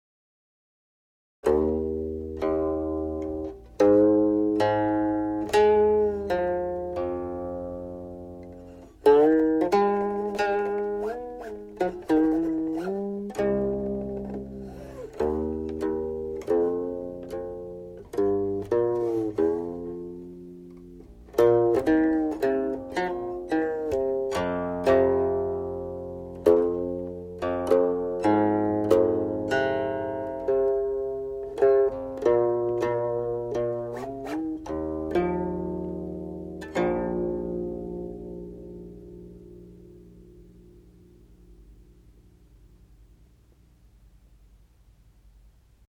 Return to the Guqin ToC (return to "sound")